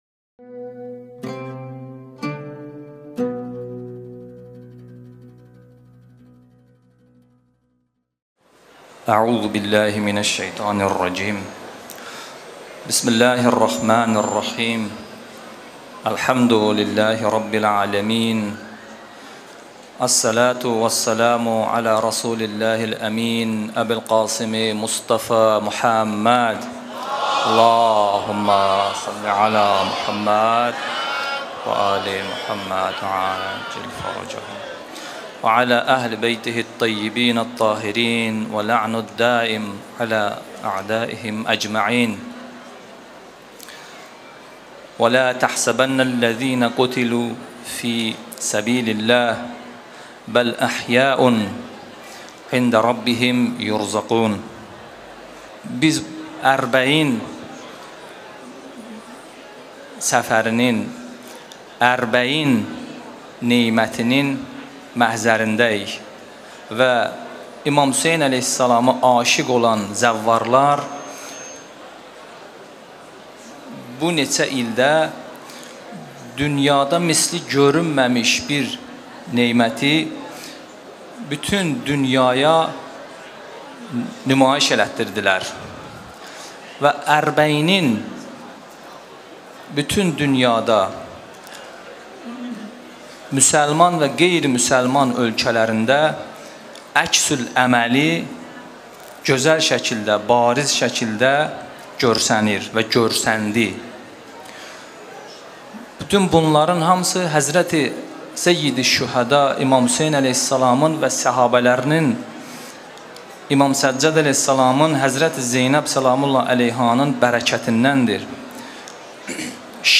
اجتماع زائران آذری زبان
عراق، صافی صفا | روز دوم پیاده روی اربعین 97